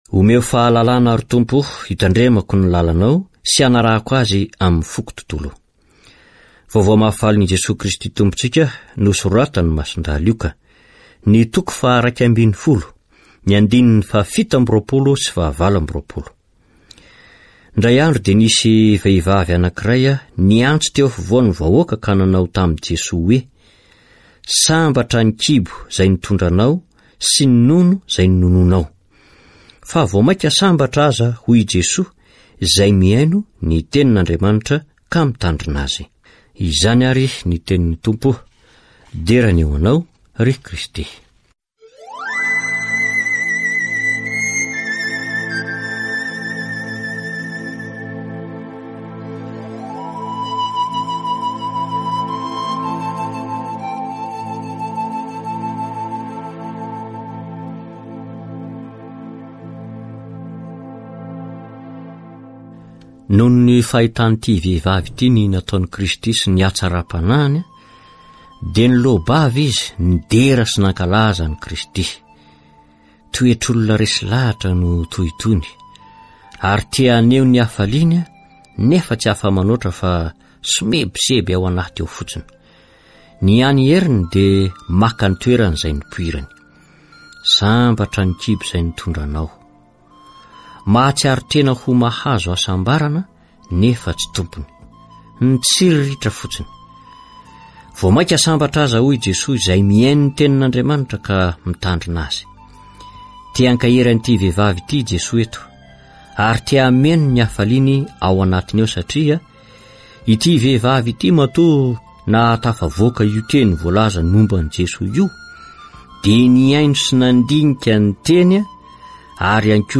Catégorie : Évangile